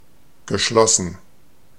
Ääntäminen
IPA : /ˈkloʊzd/